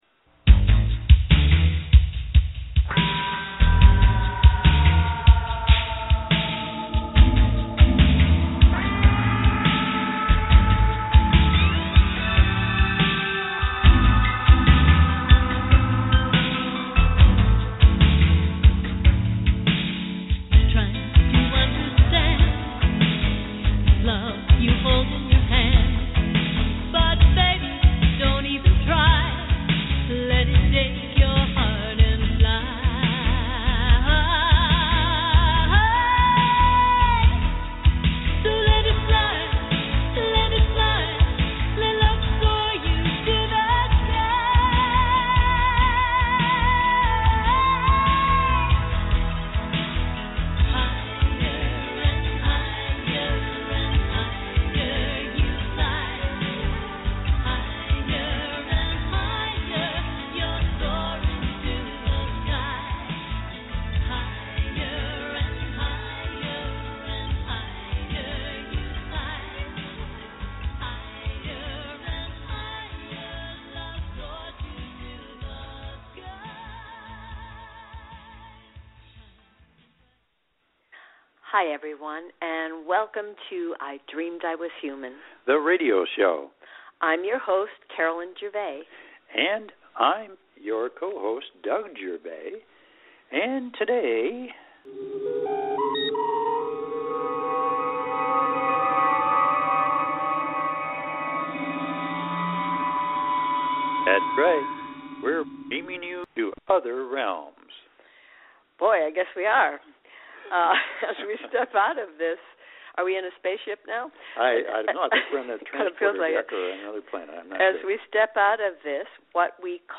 Headlined Show, I Dreamed I Was Human July 18, 2015